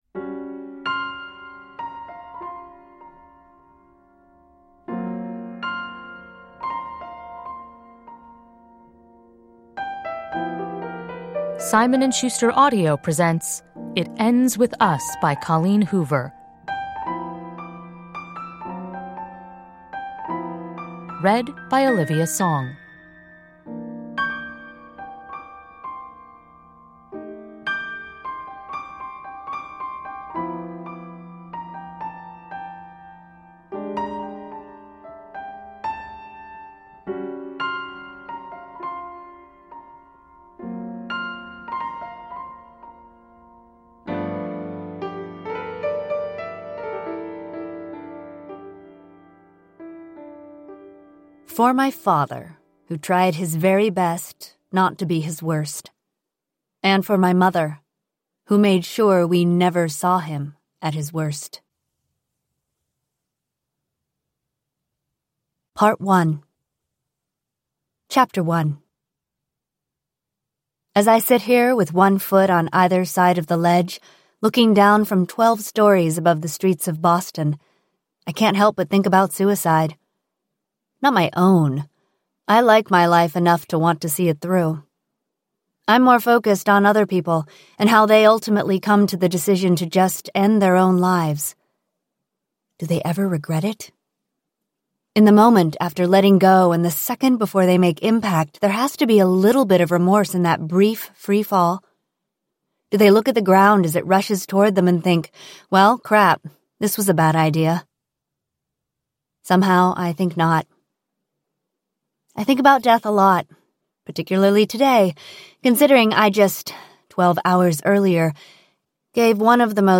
It Ends with Us – Ljudbok – Laddas ner